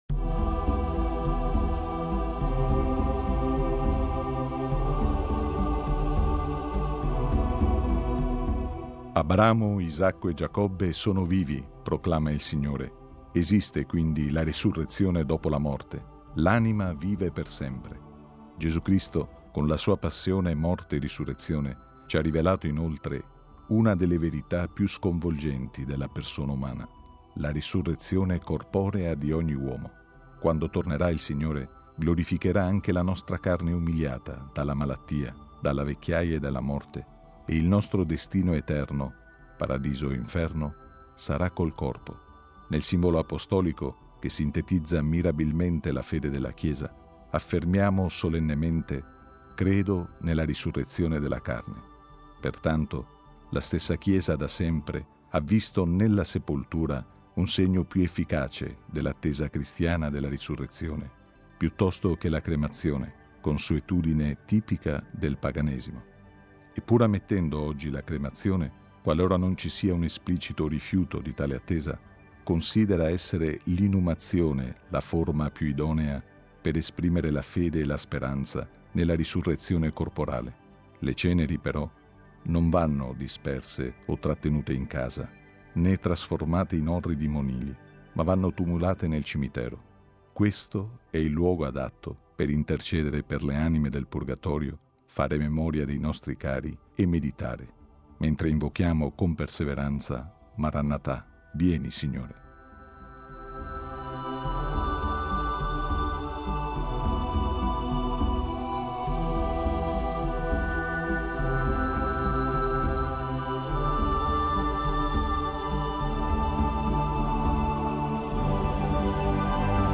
Su questo brano evangelico ascoltiamo una breve riflessione